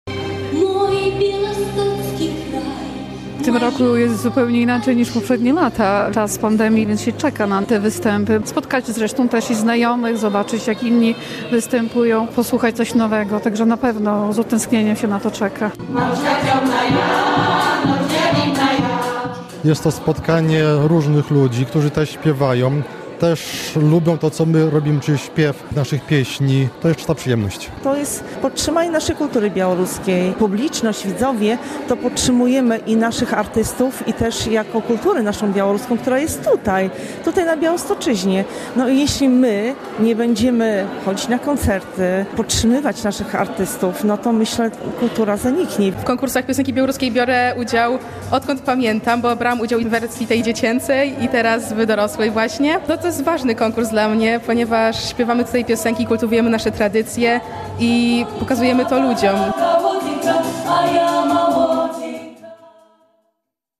Koncert galowy XXIX Ogólnopolskiego Festiwalu Piosenki Białoruskiej - relacja